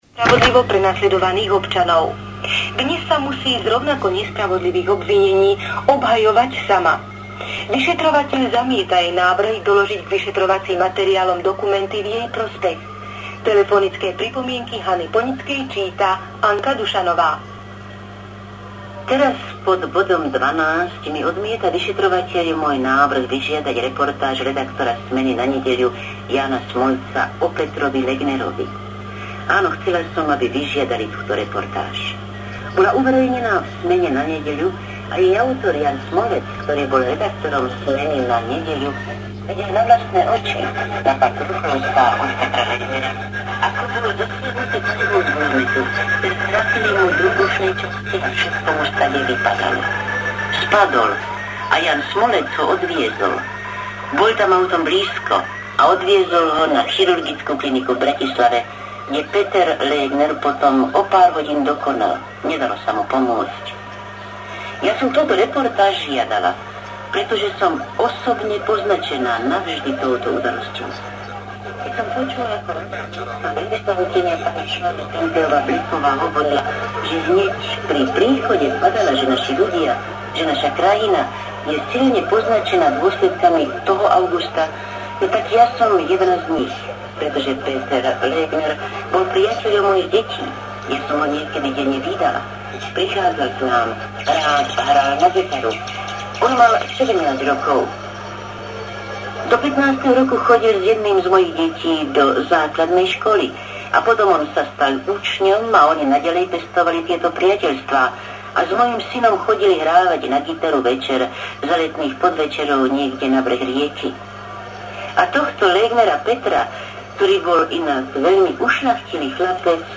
Z vysielania Hlasu Ameriky a Rádia Slobodná Európa  (august – november 1989)    August 1989